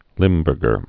(lĭmbûrgər)